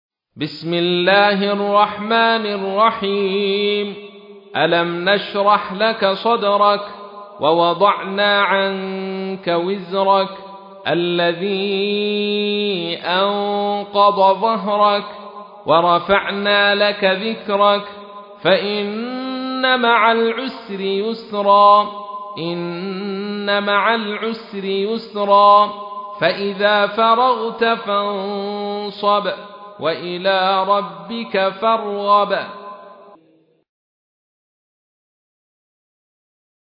تحميل : 94. سورة الشرح / القارئ عبد الرشيد صوفي / القرآن الكريم / موقع يا حسين